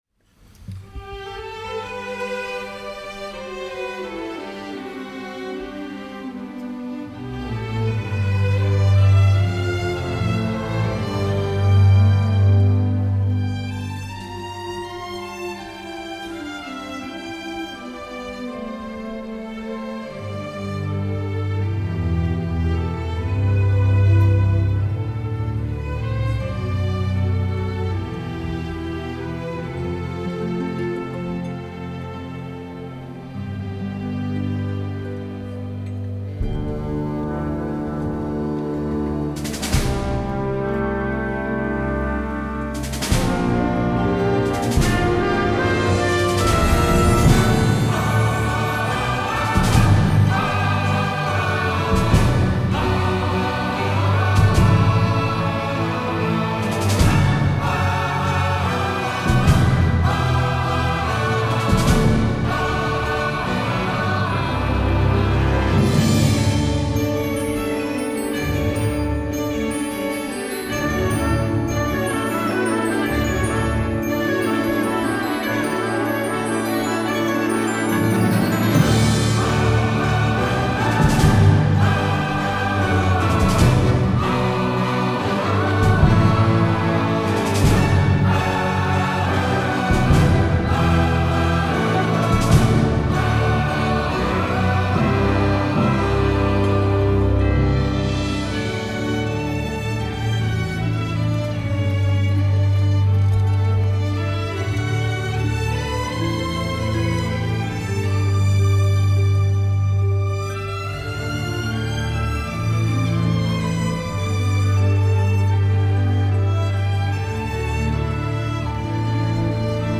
Live-Performance
in October 2024 at the Konzerthaus, Vienna:
vocal
chorus